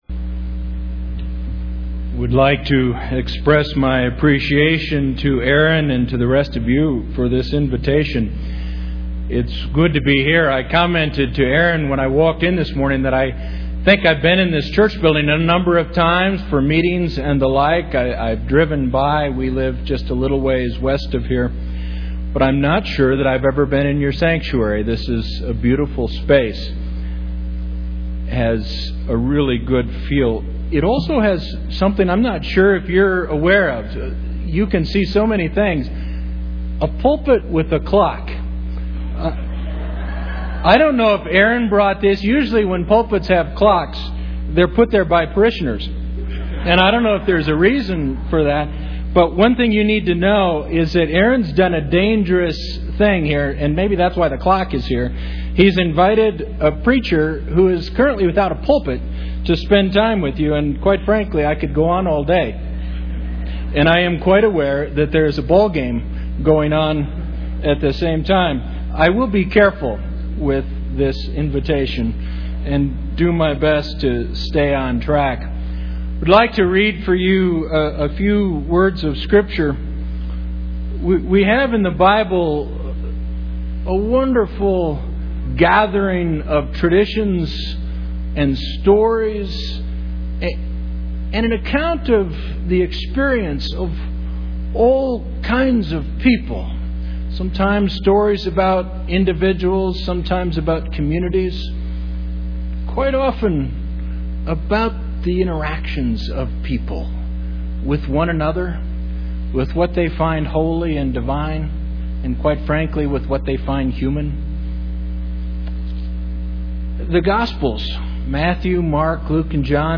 Sunday's Sermon
We had a faulty, remote mike, which was not muted during the sermon's recording.